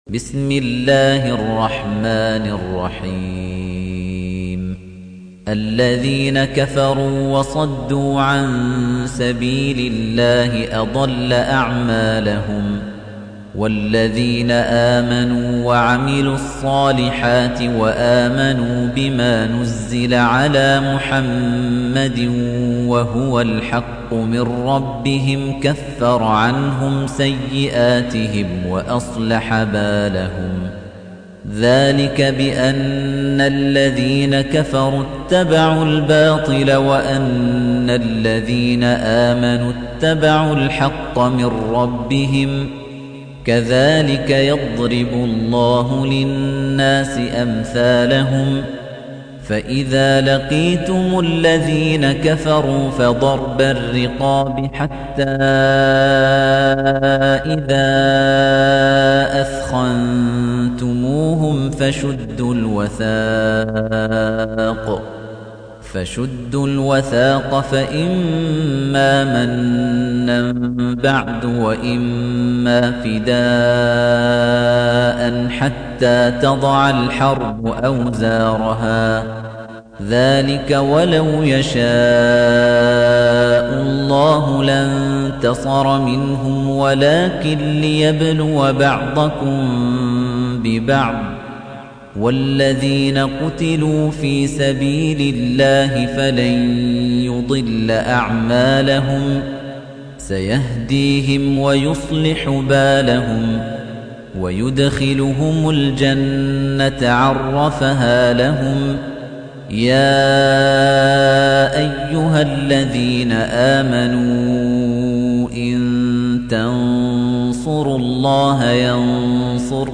تحميل : 47. سورة محمد / القارئ خليفة الطنيجي / القرآن الكريم / موقع يا حسين